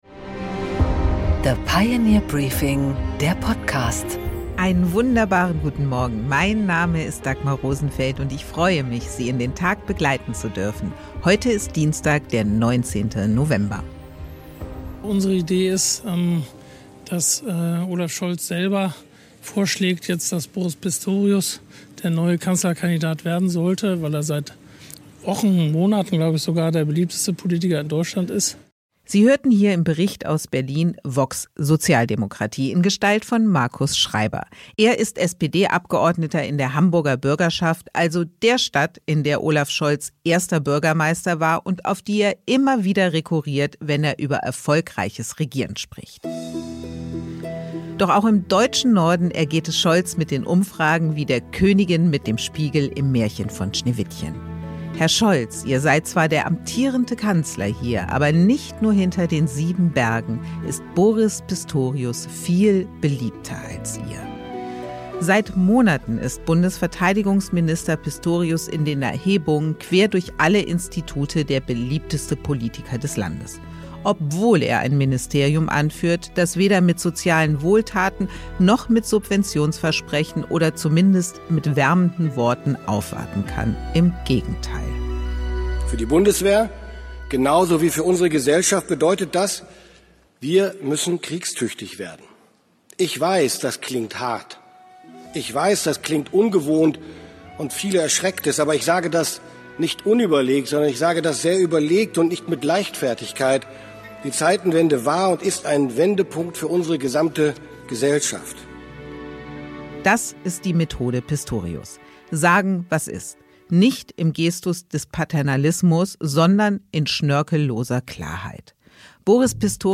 Im Interview: Carsten Spohr
Dagmar Rosenfeld präsentiert das Pioneer Briefing